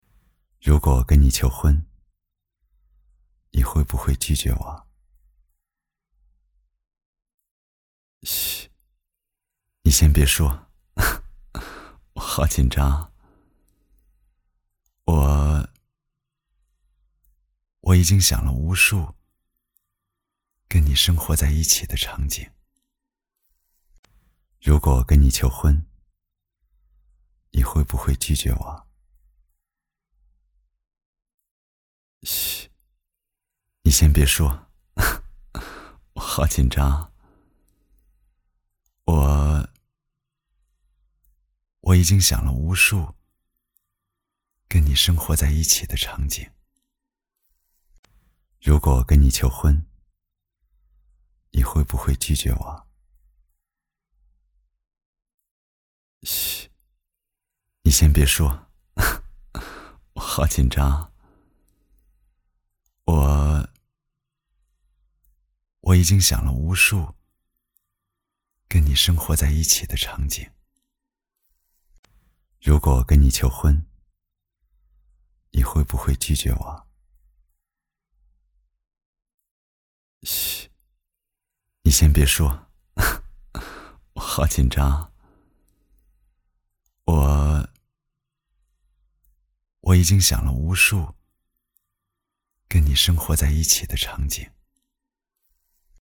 • 男1 国语 男声 独白 旁白 如果给你求婚 自然 感人煽情|素人